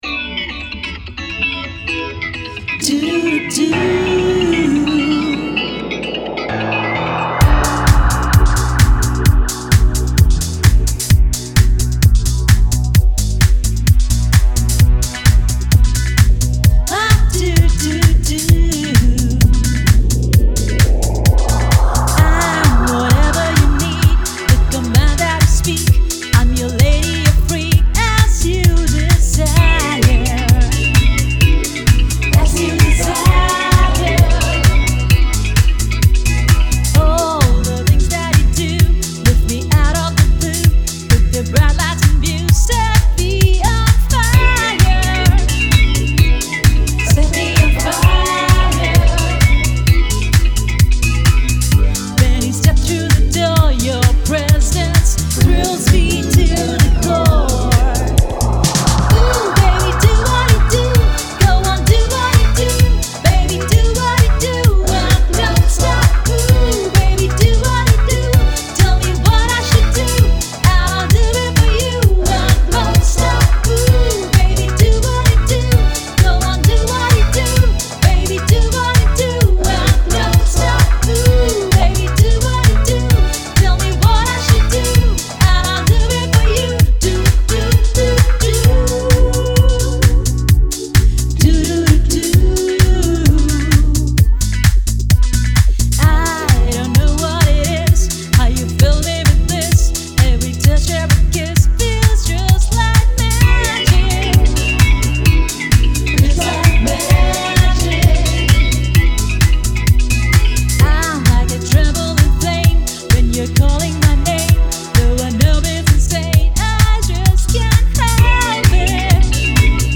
Demo
With a playful, seductive tone